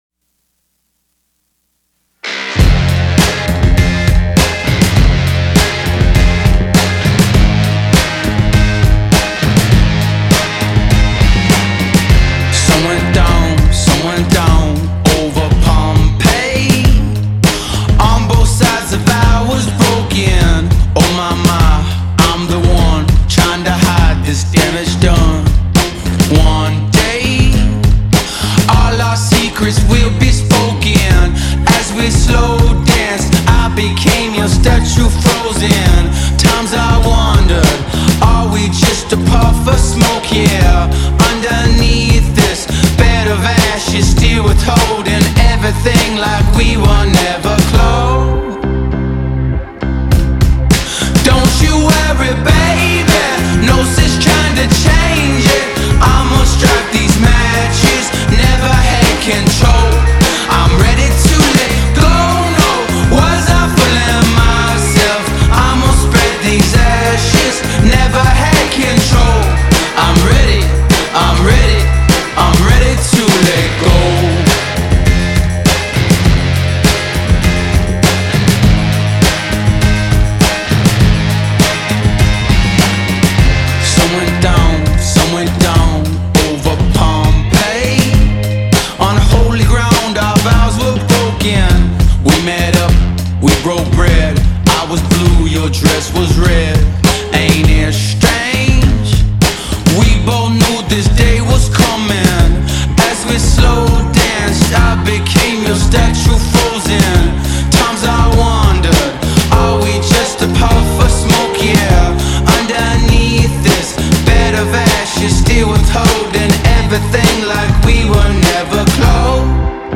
alternative music